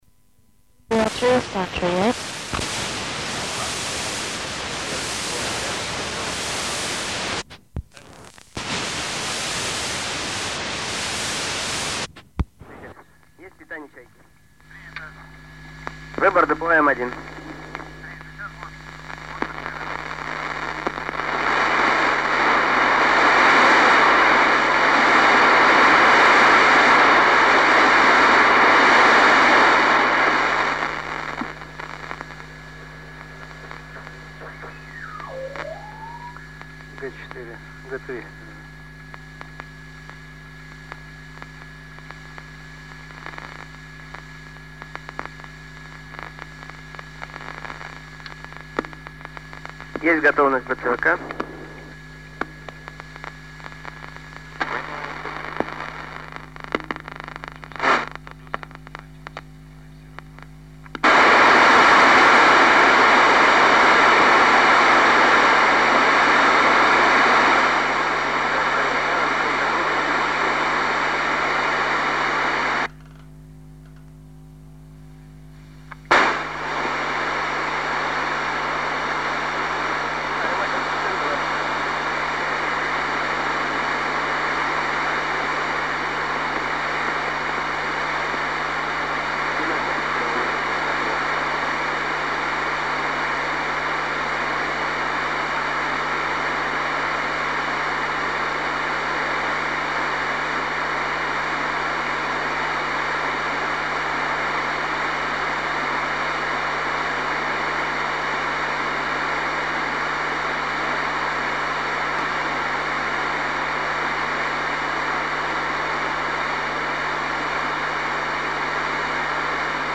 Voice recordings "re-discovered" in 2018